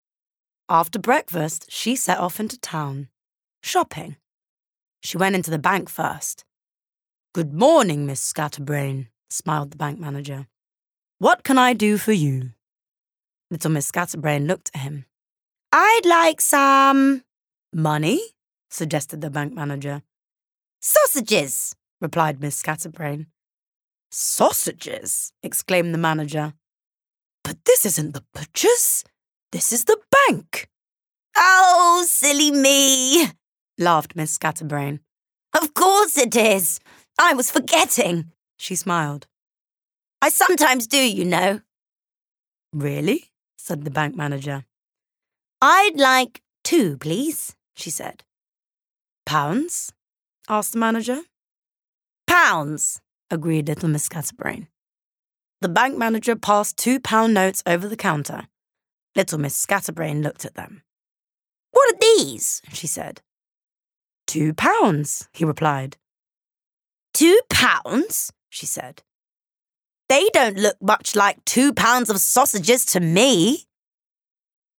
Essex, RP ('Received Pronunciation')
Audio Book Kids Animation Humour